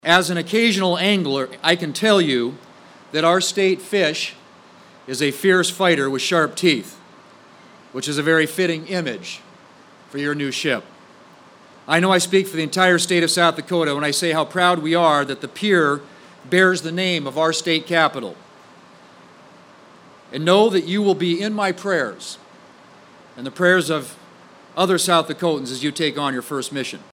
PANAMA CITY, F.L.(KCCR)- The U-S-S Pierre officially joined the fleet of the United States Navy Saturday morning with a traditional Commissioning Ceremony at Port Panama City Florida.
South Dakota Senator John Thune says it’s fitting the Pierre’s crest features two walleye on either side.